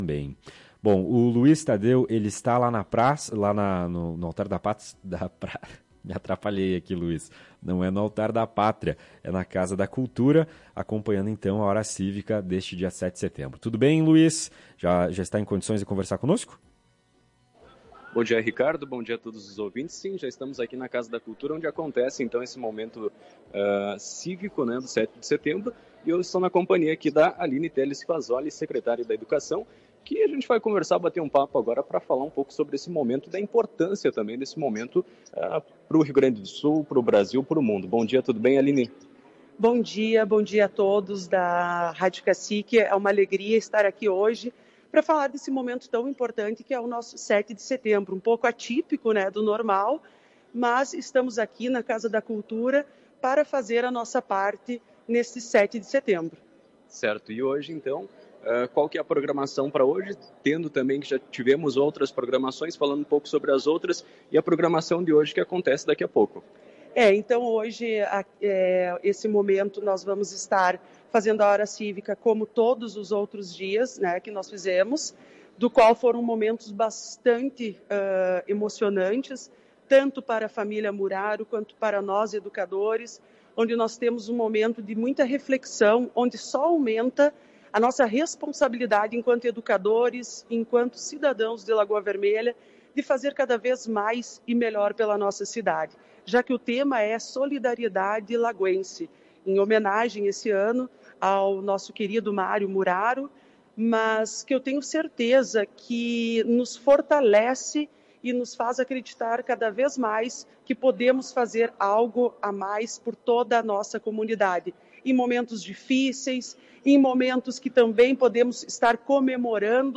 Em entrevista à Tua Rádio Cacique, ela avaliou as homenagens prestadas e a importância da data para os brasileiros.